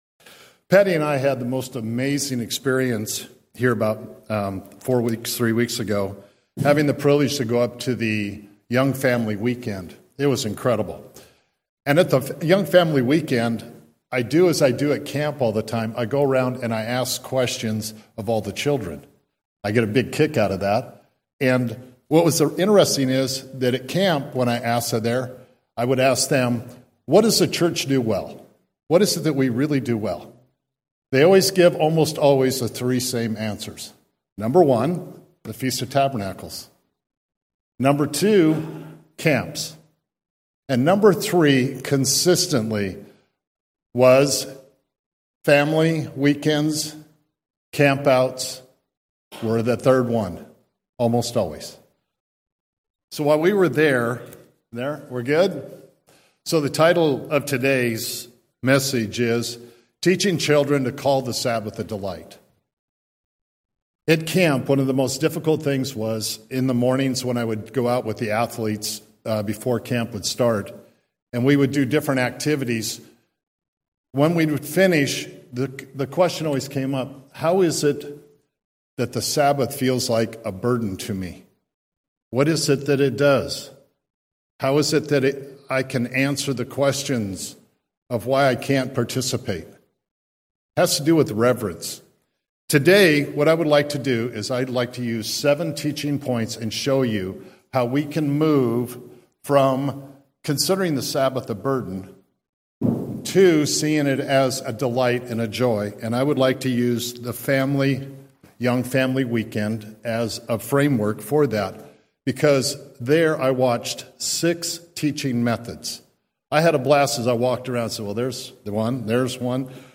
The Sabbath was never meant to be a burden but a joy-filled day of worship, fellowship, and renewal. This presentation explores how children can learn to call the Sabbath a delight through simple examples, hands-on activities, and family traditions that point to God’s purpose for His holy day.